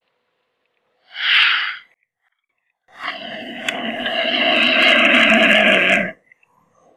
buitrecabezanegra.wav